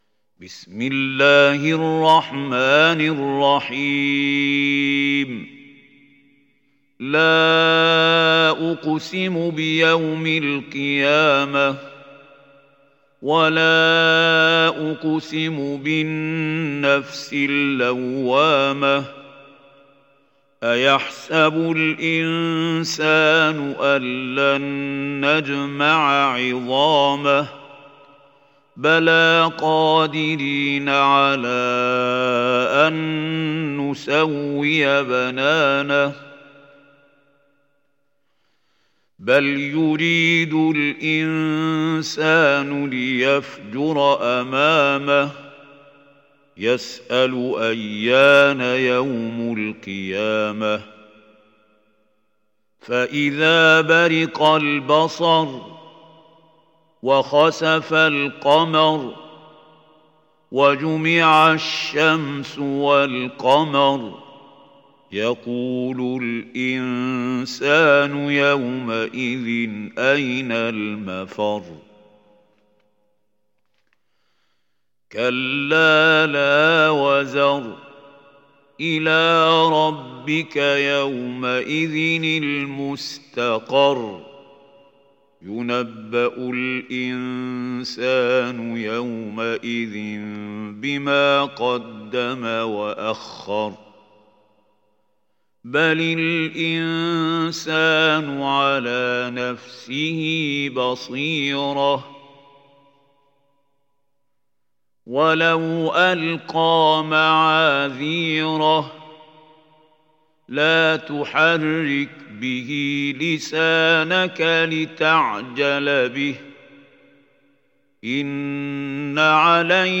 دانلود سوره القيامه mp3 محمود خليل الحصري (روایت حفص)